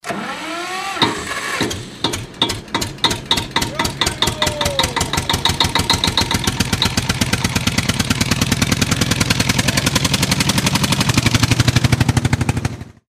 Kategorien: Soundeffekte